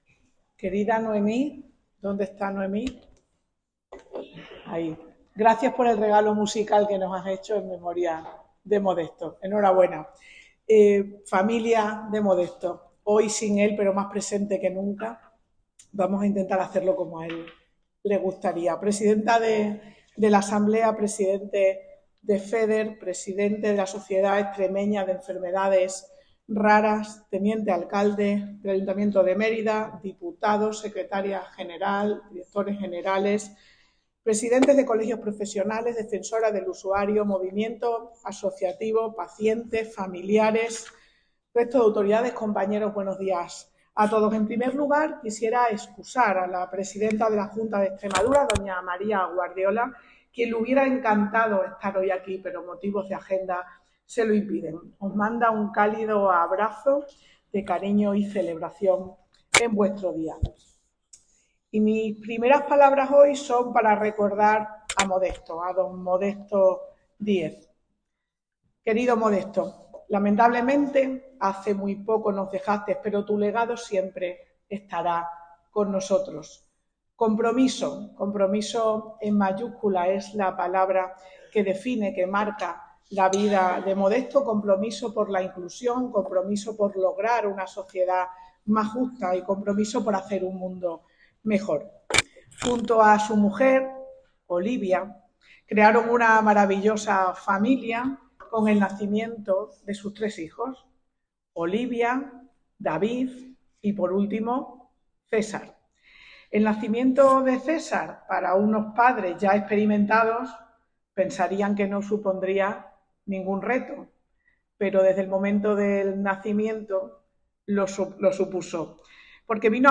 Noticias Extremadura crea la figura del enfermero gestor de casos para enfermedades raras en la consulta de transferencia 21/03/2025 SALUD EXTREMADURA Documentos relacionados Declaraciones de la consejera de la consejera de Salud y Servicios Sociales, Sara G� Espada .